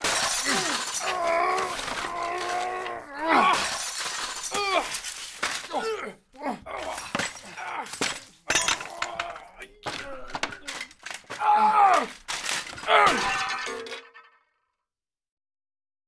Dos hombres peleándose
pelea
Sonidos: Acciones humanas
Sonidos: Voz humana